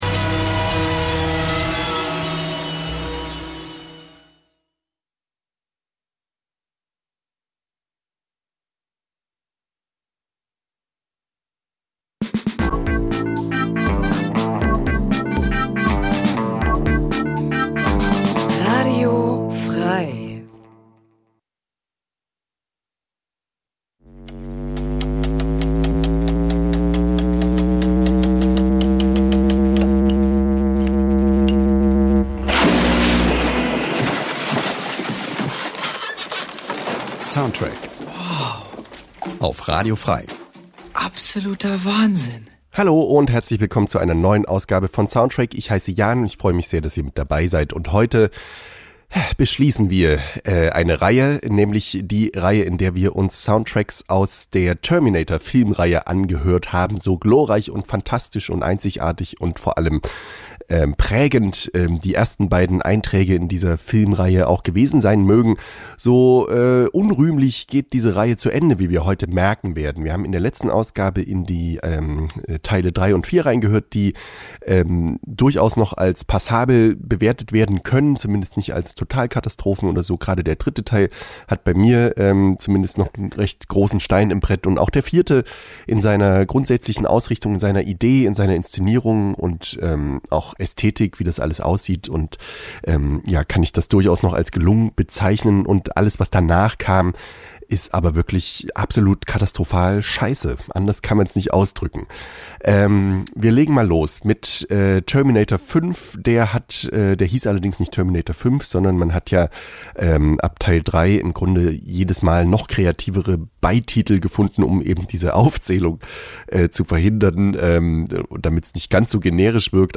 Wöchentlich präsentieren wir ausgesuchte Filmmusik.